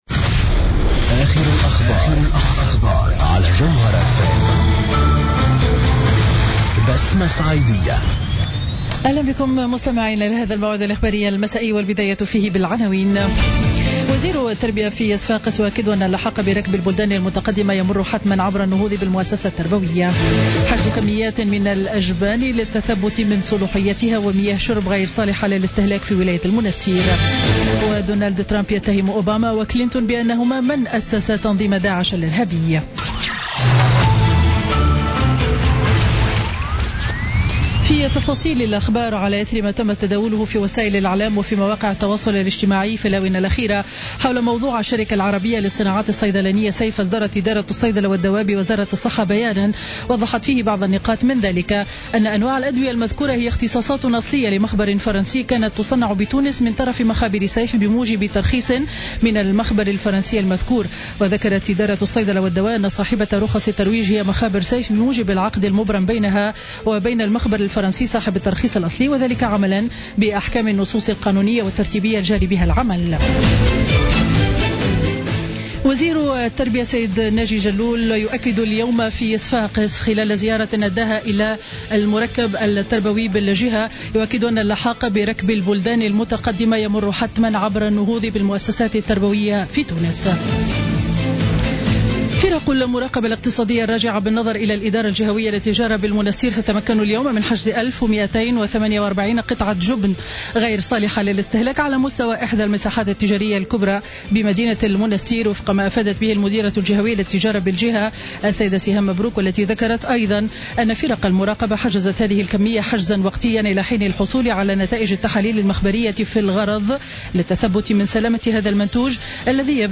نشرة أخبار السابعة مساء ليوم الخميس 11 أوت 2016